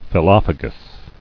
[phyl·loph·a·gous]